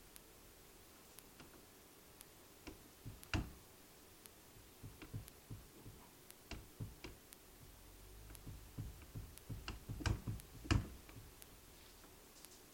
随机的" 敲击木质卧室门附近的房间不错的各种
描述：敲木卧室门附近宽敞漂亮各种各样